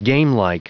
Prononciation du mot gamelike en anglais (fichier audio)
Prononciation du mot : gamelike